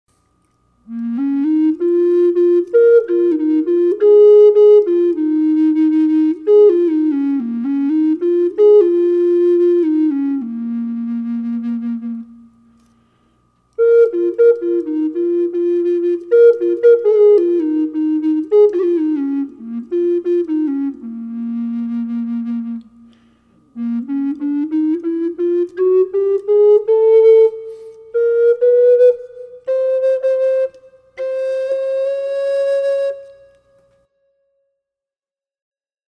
Sound sample of  this Flute short melody + 16 notes scale  with a light reverb
low-A-sharp-Bass-reverb.mp3